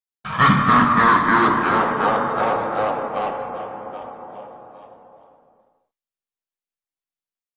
kolossos laughter Meme Sound Effect
kolossos laughter.mp3